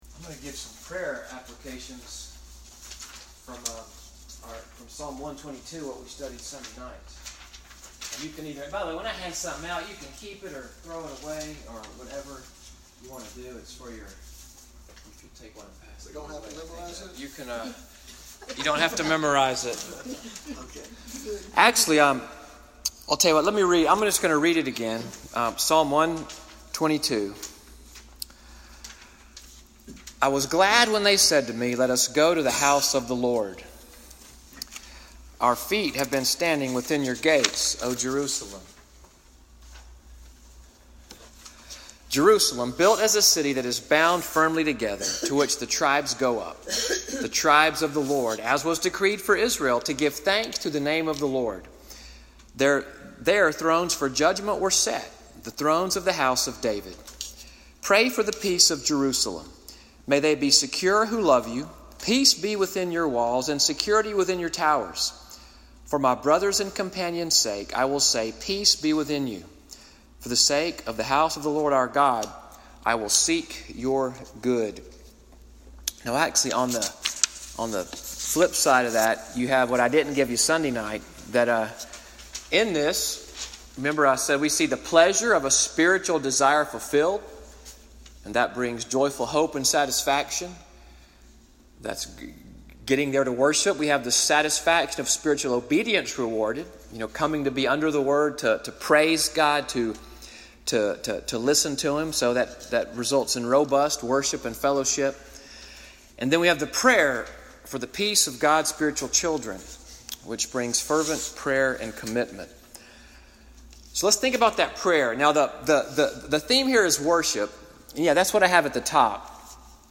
Evening Worship at NCPC-Selma, audio from the sermon, “A Spiritual Home” April 15, 2018.